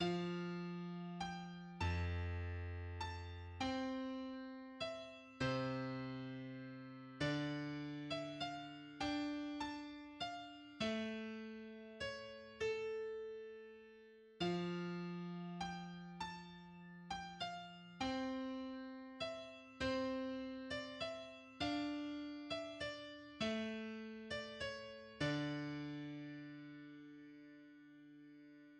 La Romanesca est une basse obstinée utilisée pour la danse.